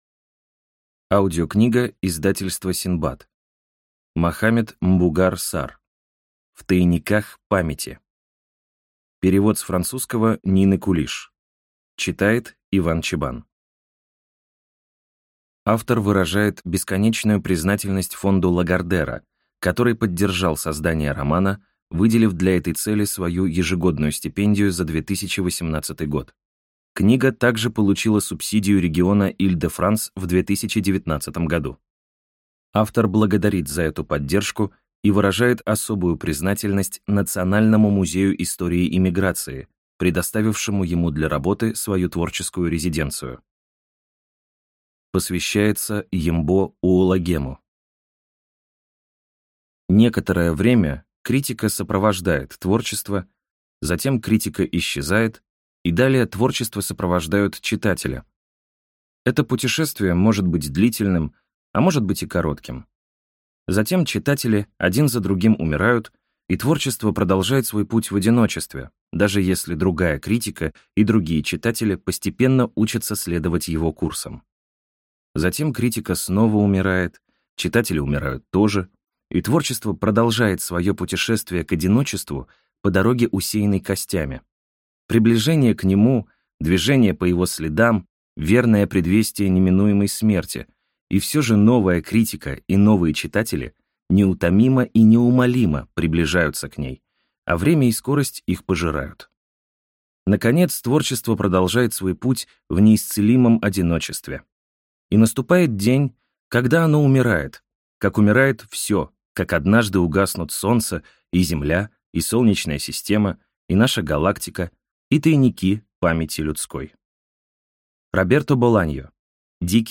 Аудиокнига В тайниках памяти | Библиотека аудиокниг